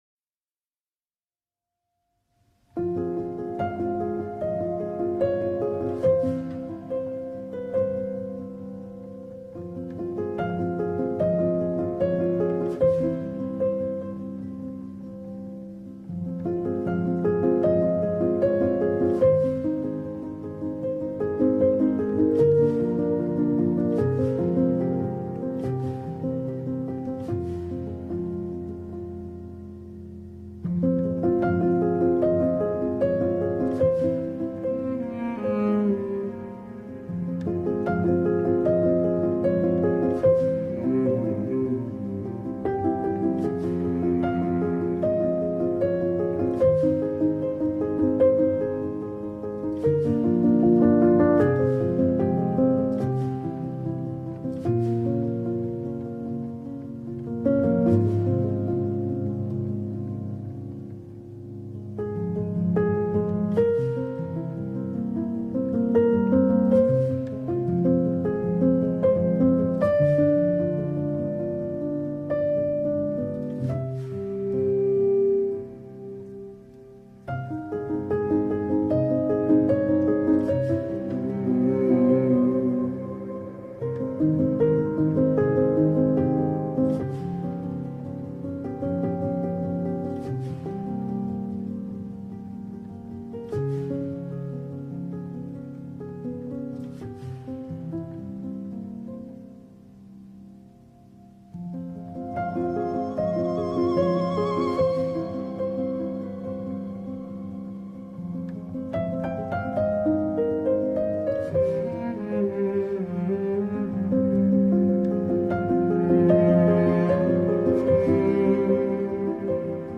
Klasik-Muzik-Chopin-Rahatlatici-Klasik-Muzik-1-1.mp3